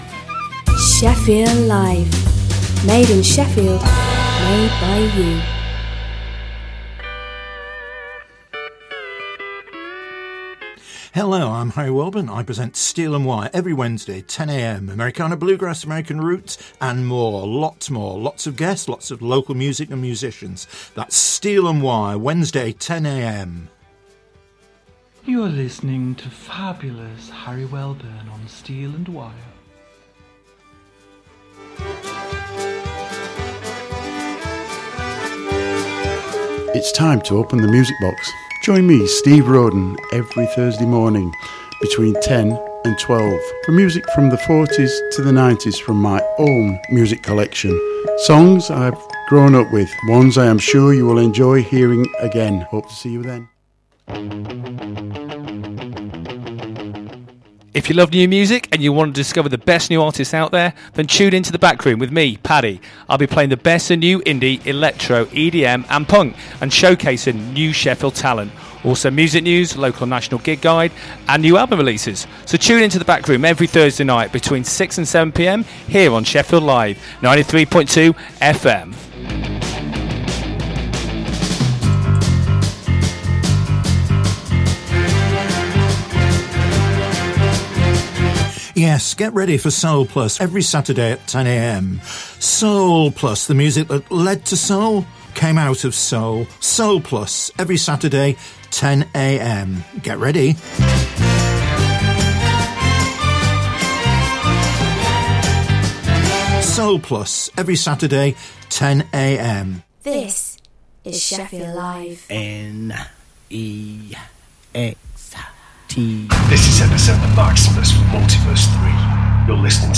2 hours of the best popular classic and Prog rock music plus Gig and band info..